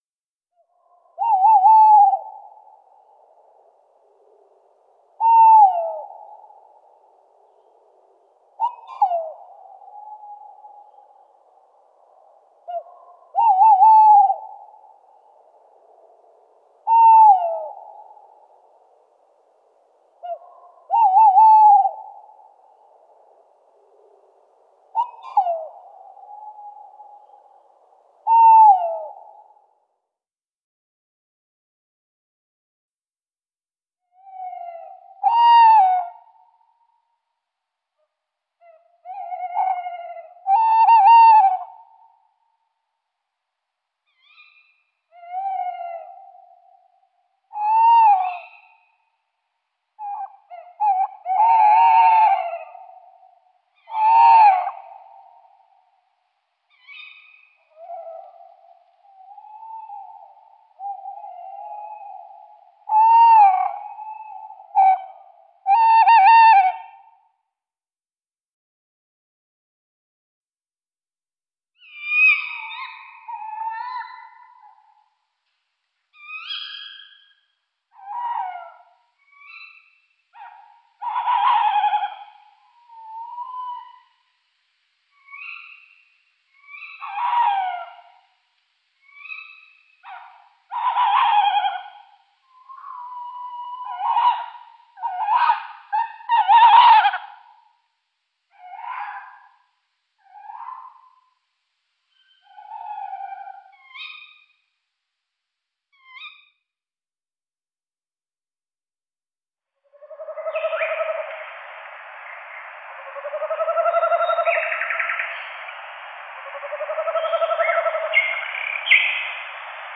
🔎 Zoom sur La chouette hulotte
Lors d’un crépuscule ou d’une nuit, qui n’a jamais entendu ces hululements retentissants ?🌙
Le mâle émet le fameux chant, composé d’un « ouuuuu » prolongé. La femelle répond au mâle par des « kiwit » bisyllabiques.